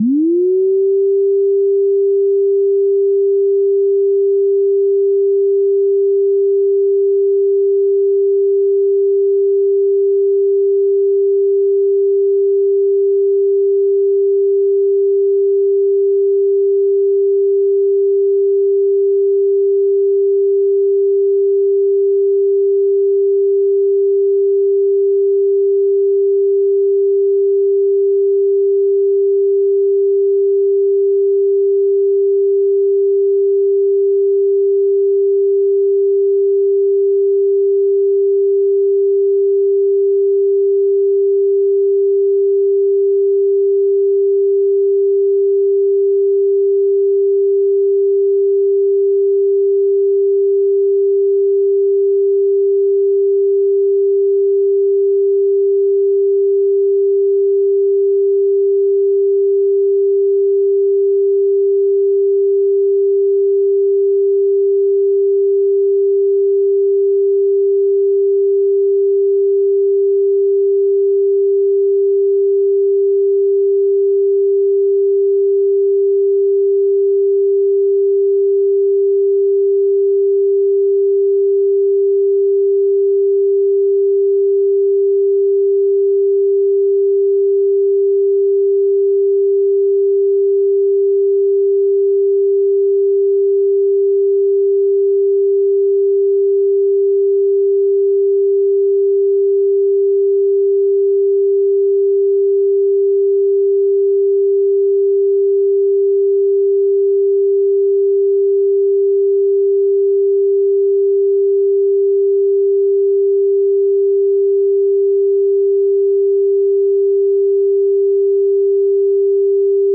396 Hz Tone Sound Solfeggio Frequency
Solfeggio Frequencies